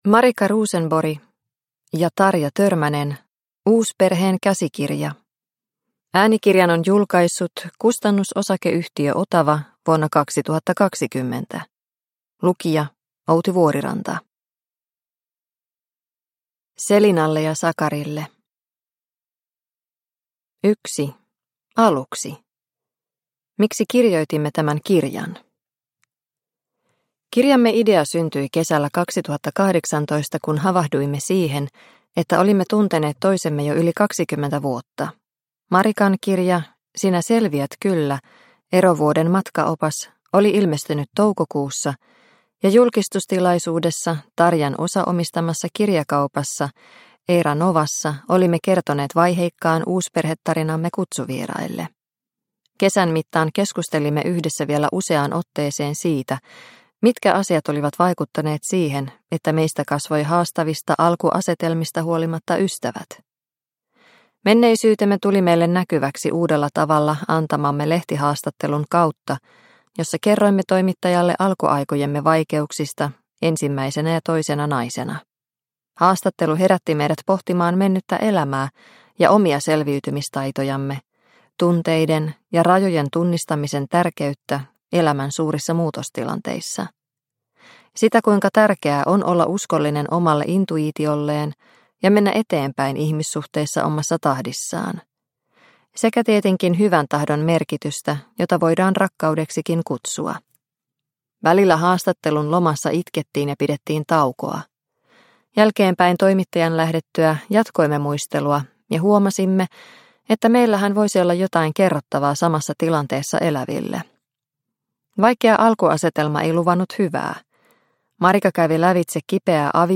Uusperheen käsikirja – Ljudbok – Laddas ner